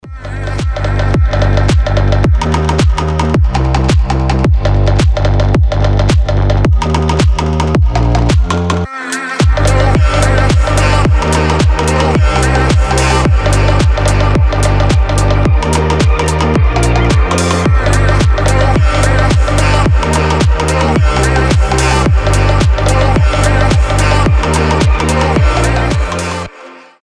• Качество: 320, Stereo
deep house
атмосферные
басы
Чрезвычайно погружающая музыка